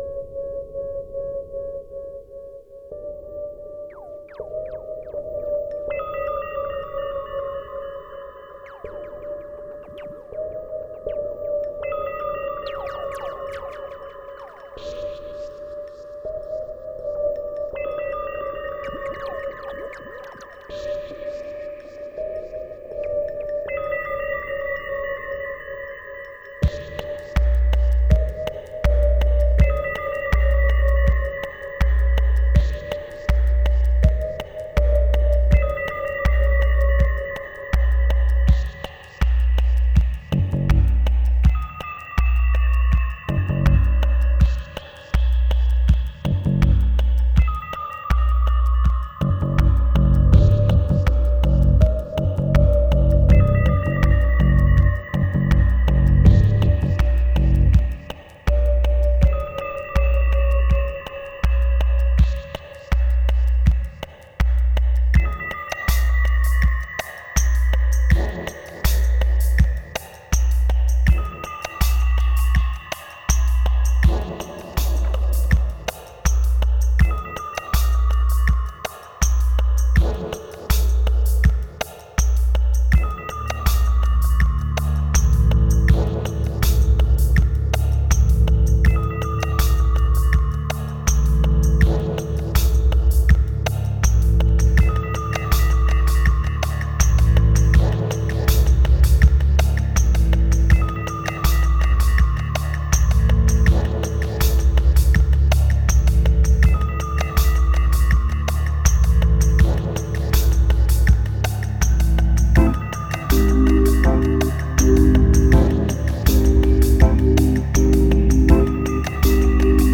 2242📈 - 38%🤔 - 81BPM🔊 - 2012-11-06📅 - -9🌟